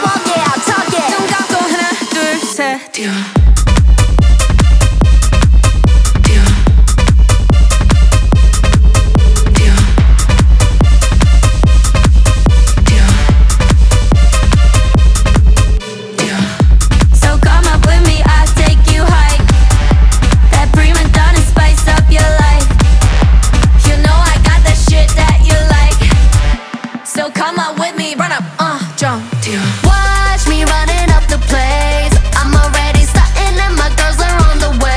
K-Pop
Жанр: Поп музыка Длительность